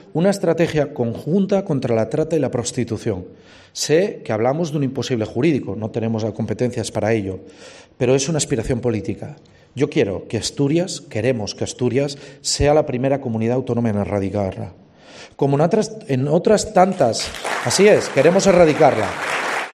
El presidente del Principado anima a los hombres a rebelarse contra la violencia que sufren las mujeres en el acto institucional con motivo del 25-N
En su intervención en el acto institucional que se celebra hoy en Nava con motivo del Día Internacional de la Eliminación de la Violencia contra las Mujeres, Barbón ha destacado la iniciativa de la Dirección de Igualdad para desarrollar en 2023 una estrategia contra la trata y la prostitución.